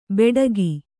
♪ beḍagi